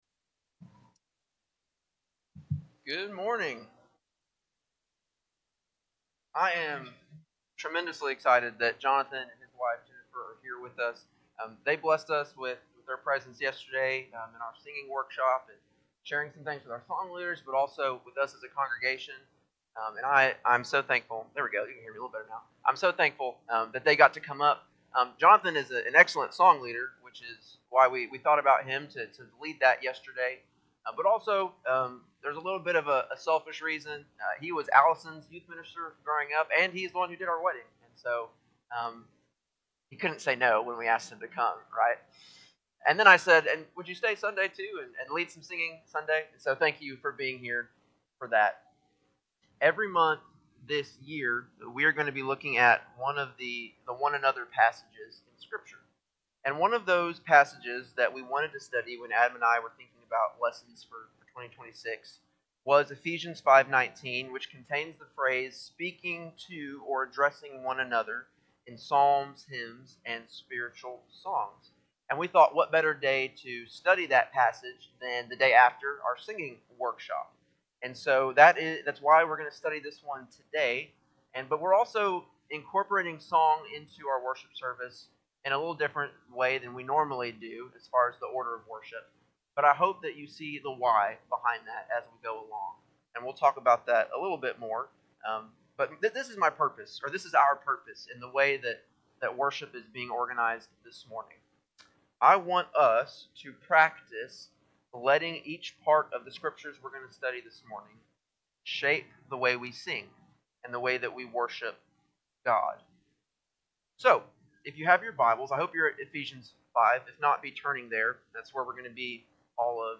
Sunday-AM-Worship-3-8-26.mp3